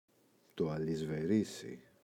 αλισιβερίσι, το [alisiveꞋrisi]